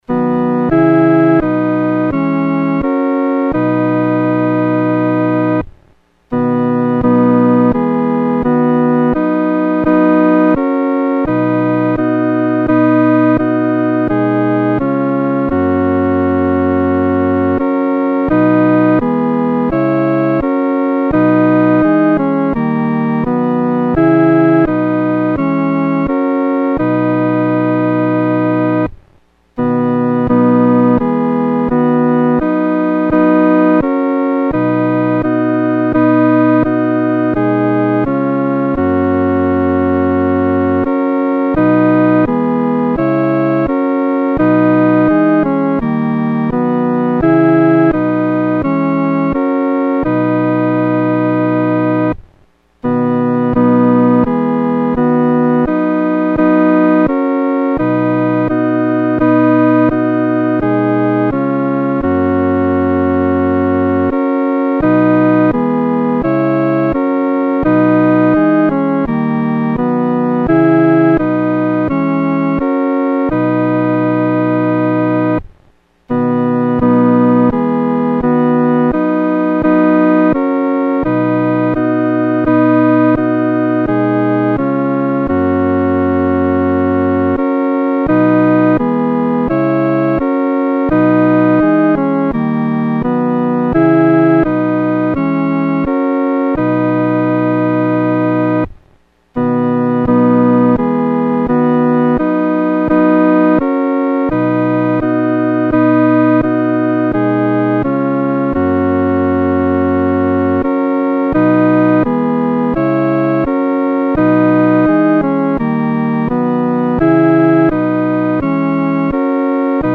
独奏（第二声）